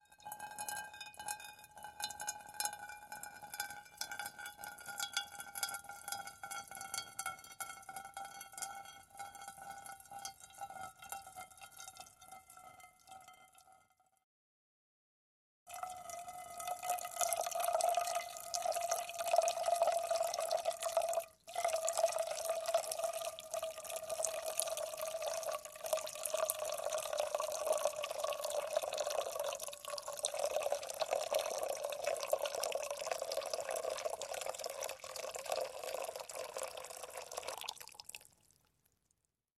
Шум наливания воды в кастрюлю